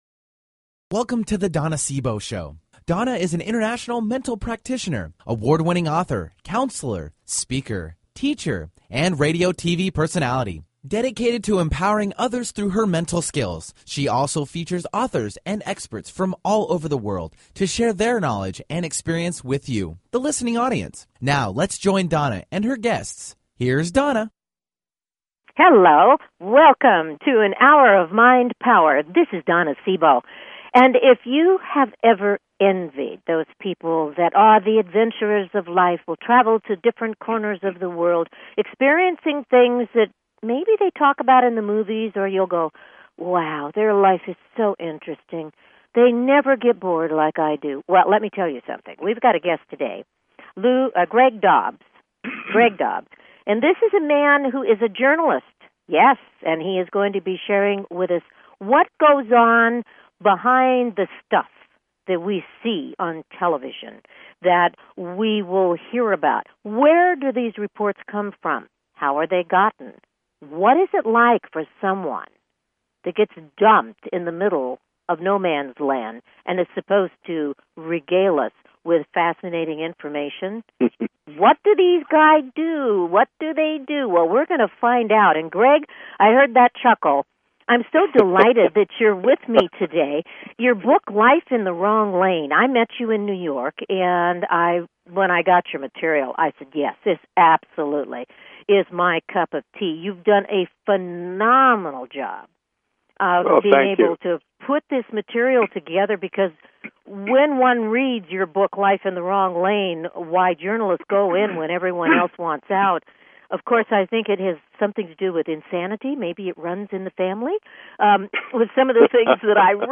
Her interviews embody a golden voice that shines with passion, purpose, sincerity and humor.
Talk Show
Tune in for an "Hour of Mind Power". Callers are welcome to call in for a live on air psychic reading during the second half hour of each show.